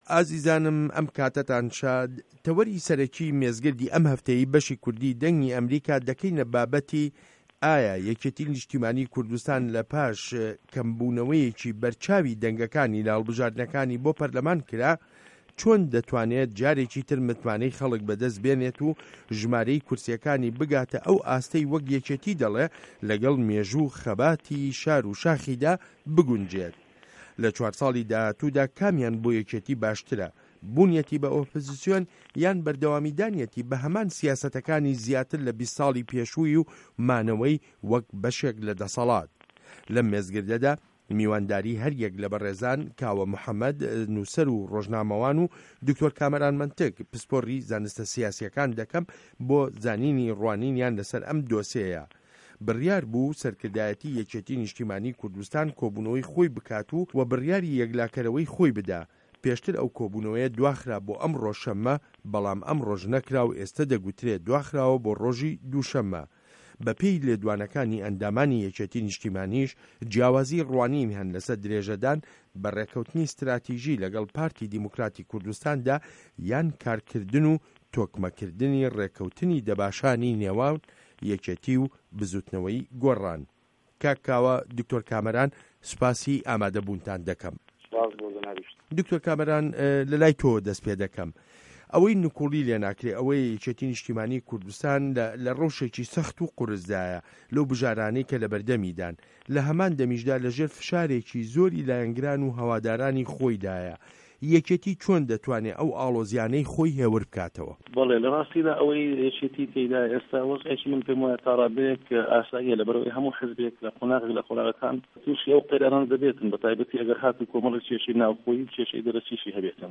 مێزگرد: یه‌کێتی نیشتیمانی کوردستان به‌ره‌و ئۆپزسیۆن یان مانه‌وه‌ له‌ ده‌سه‌ڵاتدا